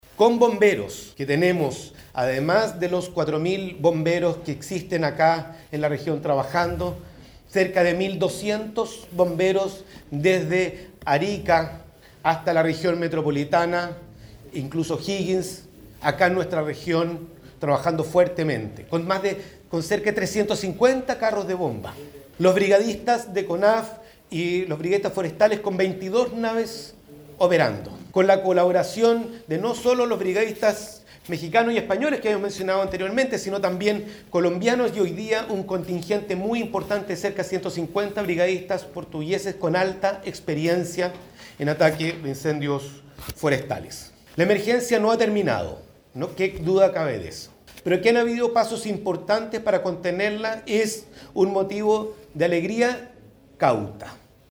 La autoridad agregó que la región lleva “tres días sin nuevos focos”, sin embargo llamó a la precaución, argumentando que “nos tocarán días de alta temperatura, lo que nos ha puesto en máxima cautela, trabajando desde ya con cortafuegos”.